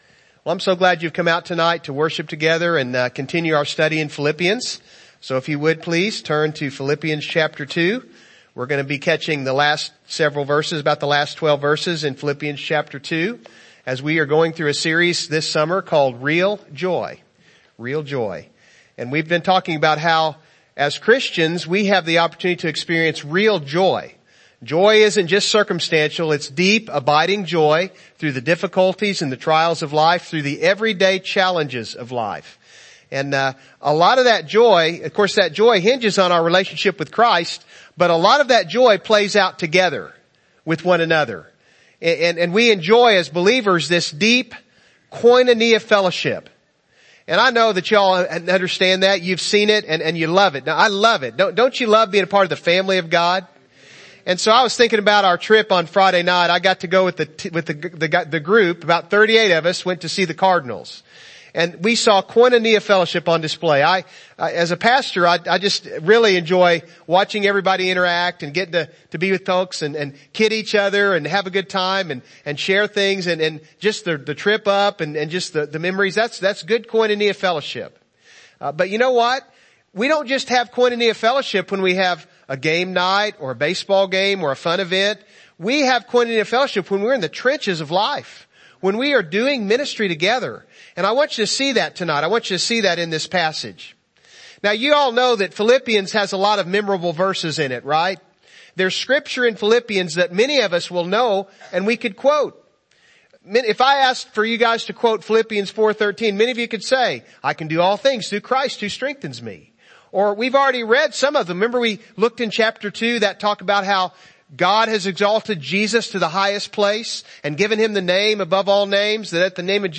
Real Joy Service Type: Evening Service « Foundational